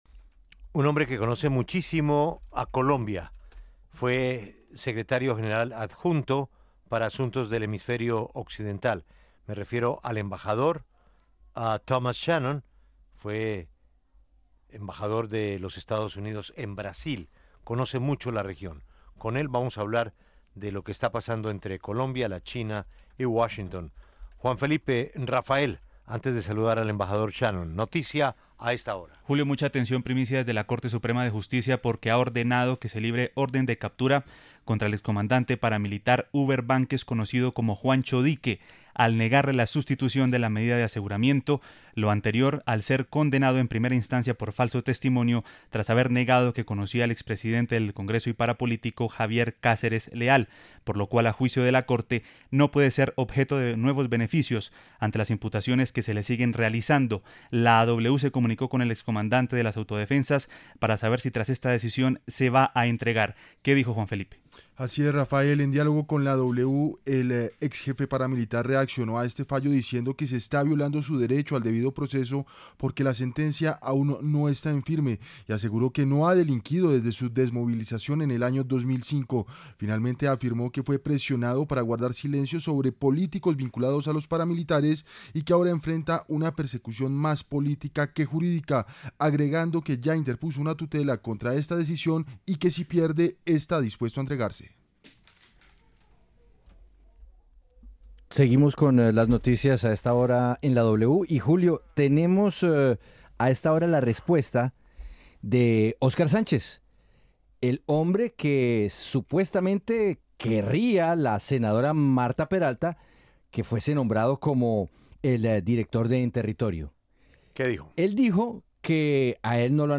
Para hablar al respecto, el ex subsecretario de Estado para Asuntos del Hemisferio Occidental de EE.UU., Thomas Shannon, pasó por los micrófonos de La W con Julio Sánchez Cristo y explicó que la relación se mantendrá en pie, pero habrá dudas de por medio.